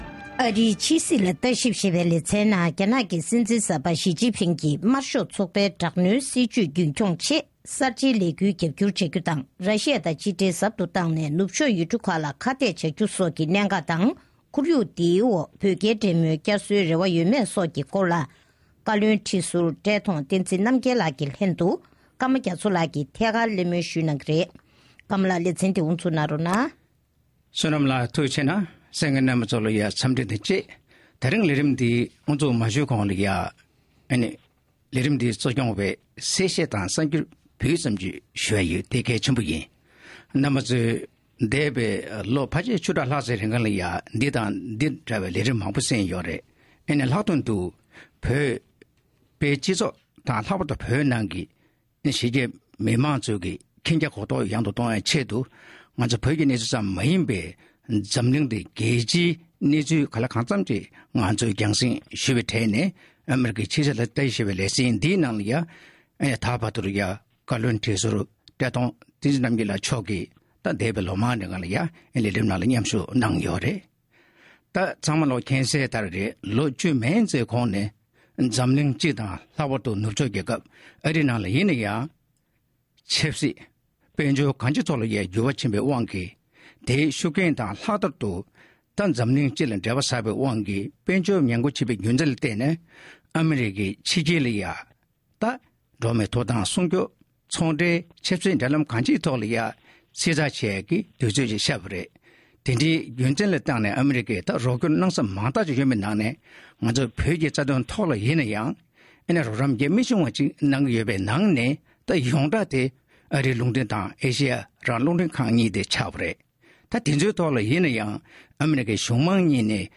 ཐད་ཀར་གླེང་མོལ་ཞུས་པ་ཞིག་གསན་རོགས་གནང་།།